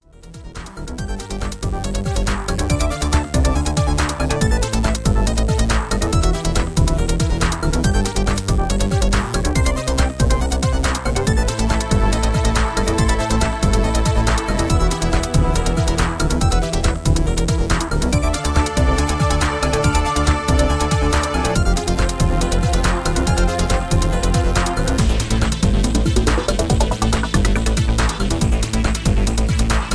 Dramatic Electro Ambient with feel of tension